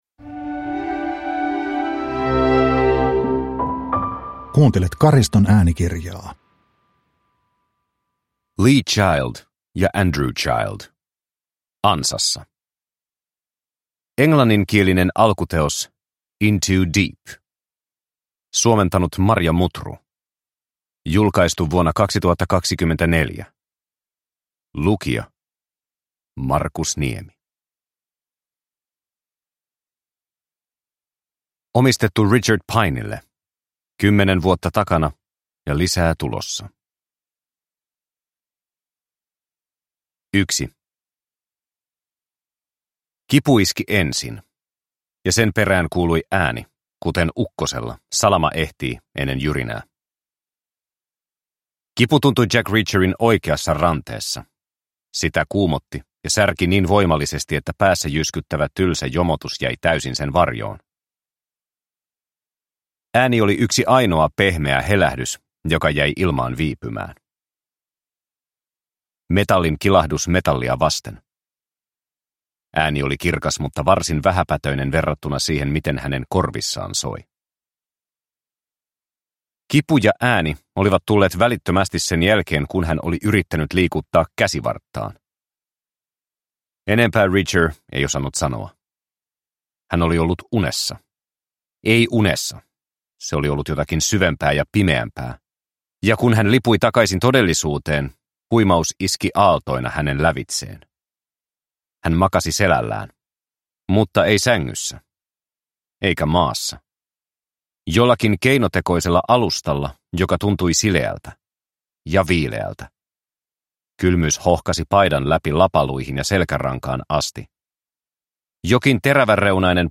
Ansassa – Ljudbok